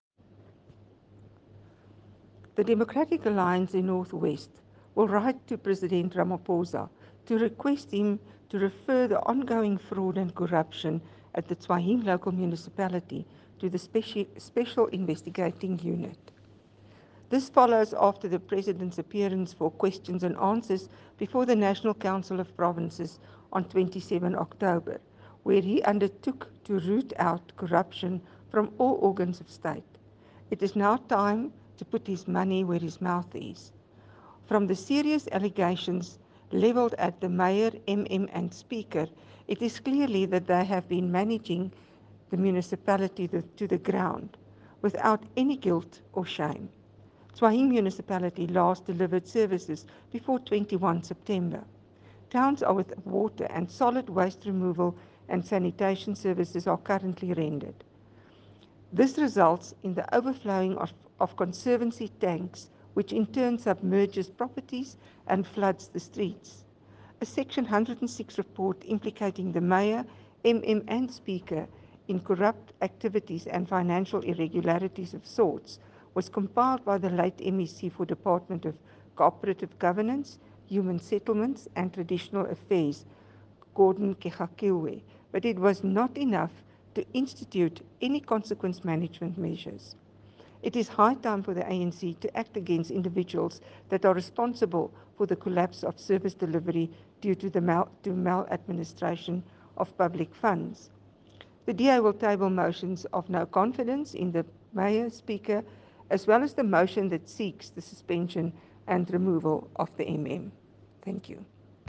Note to Editors: Find attached soundbites in
Afrikaans by DA National Council of Provinces Delegate, Carìn Visser MP.